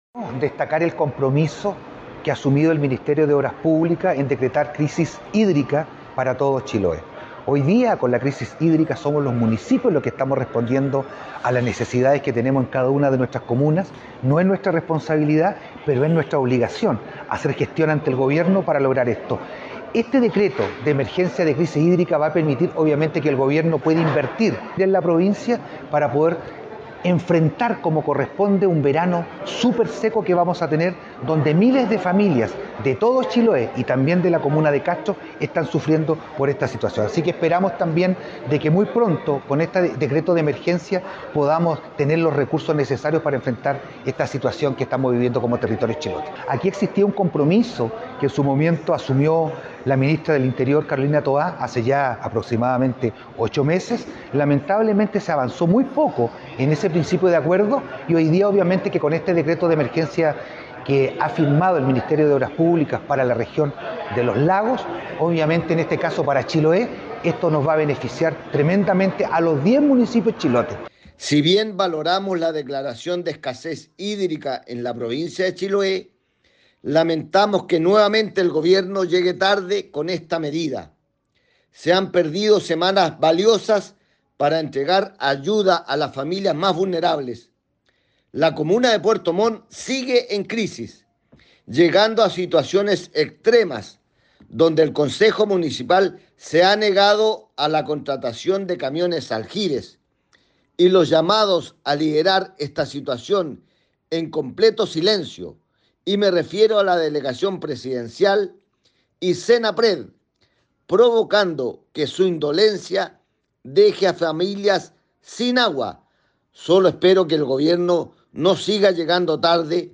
La acción del gobierno obtuvo inmediata respuesta de parte de diversos personeros como el alcalde de Castro Juan Eduardo Vera y el diputado Fernando Bórquez, quien fue muy crítico por la tardanza en efectuar esta acción por parte del ejecutivo en algo tan sensible, como el contar con agua potable.